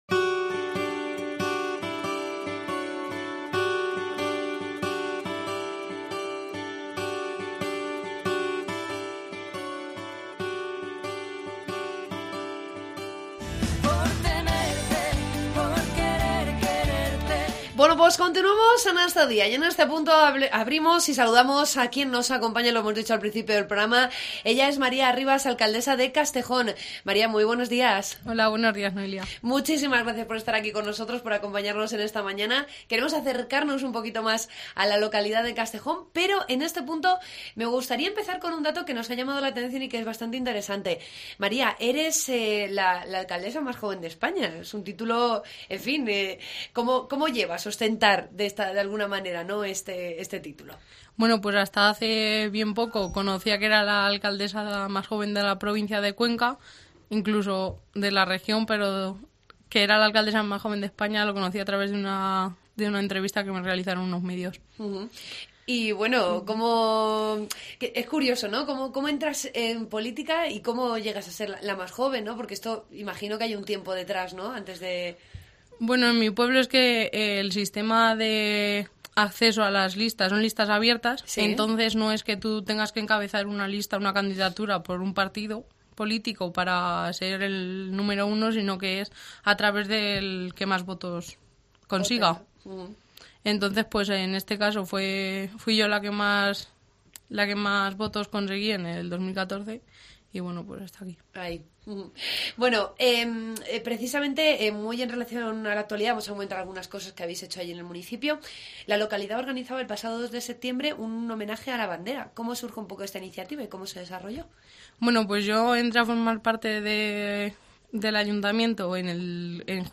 AUDIO: Entrevistamos a la alcaldesa de Castejón, María Arribas.